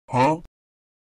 Sound Buttons: Sound Buttons View : Huh Sound Button
huh sound button Download
huh-memesoundeffects.com_.mp3